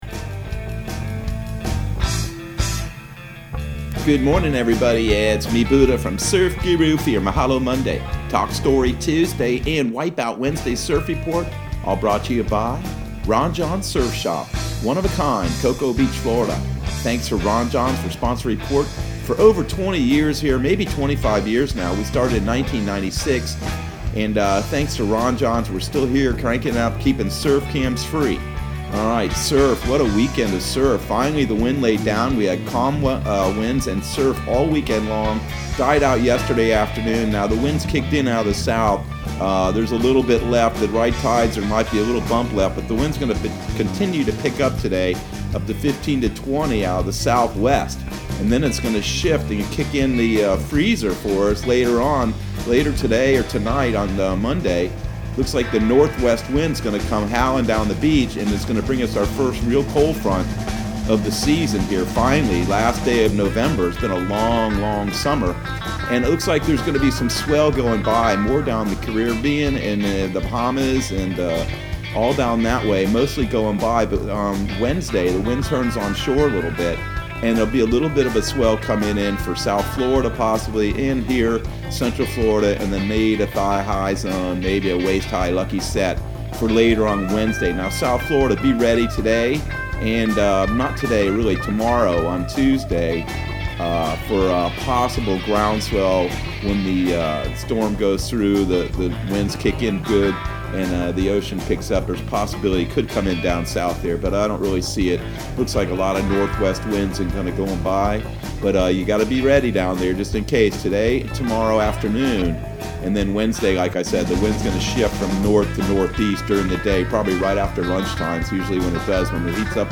Surf Guru Surf Report and Forecast 11/30/2020 Audio surf report and surf forecast on November 30 for Central Florida and the Southeast.